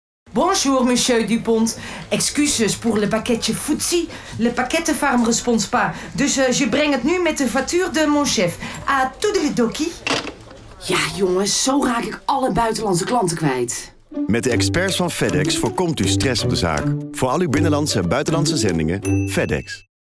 Commercials:
Fedex (komisch):